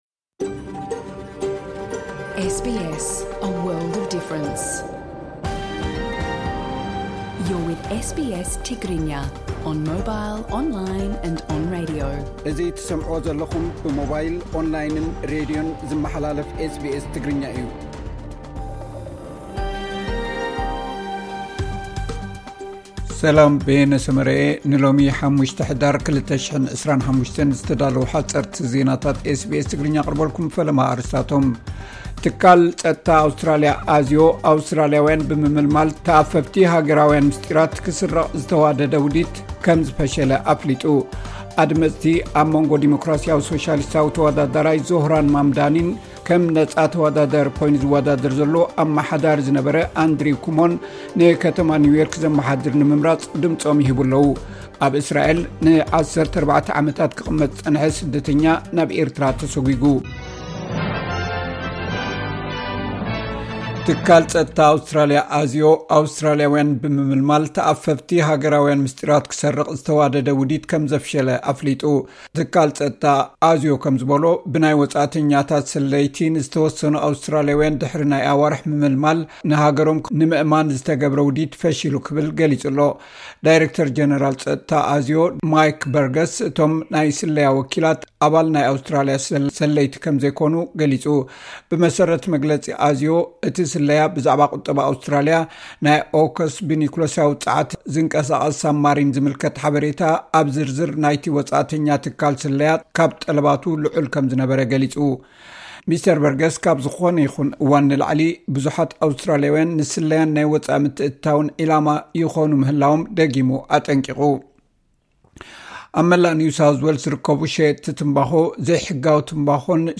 ኣመሪካ፡ ኣብ ልዕሊ ክርስትያናት ናይጀሪያውያን ዓመጽ እንተ ቀጺሉ ስጕምቲ ካብ ምውሳድ ድሕር ከም ዘይብል ገሊጻ ፡ (ሓጸርቲ ዜናታት)